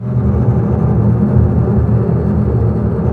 Index of /90_sSampleCDs/Roland LCDP08 Symphony Orchestra/STR_Cbs Bow FX/STR_Cbs Tremolo